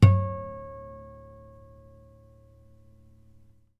strings_harmonics
harmonic-08.wav